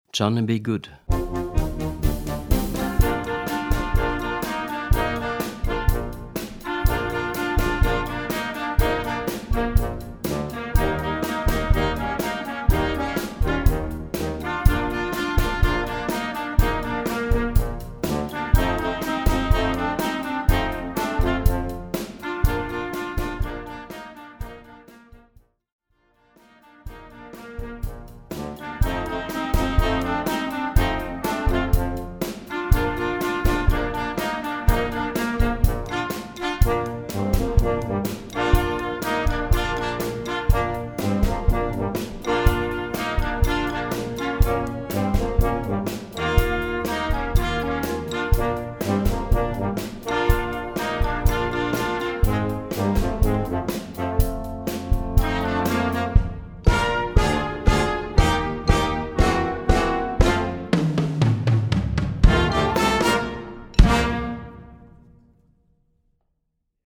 Gattung: Flexible instrumentation
Besetzung: Blasorchester